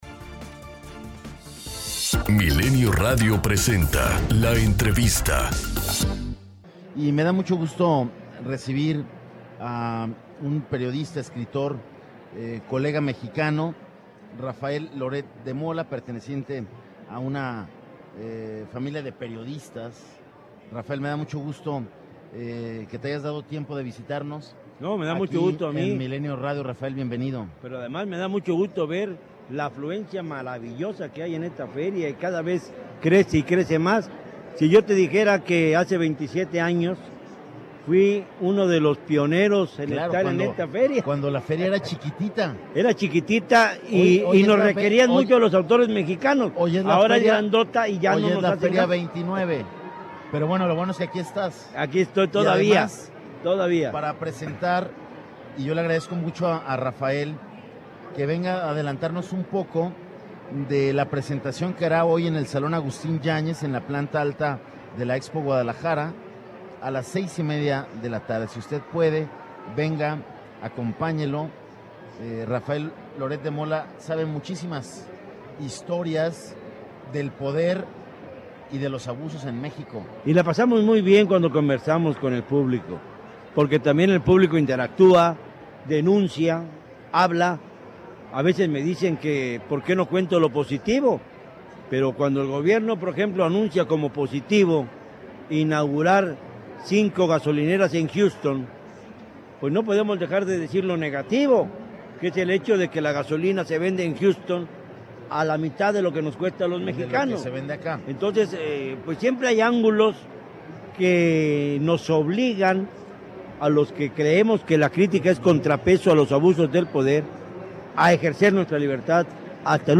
Entrevista con Rafael Loret de Mola (Libro Empeñados)
Desde la Feria Internacional del Libro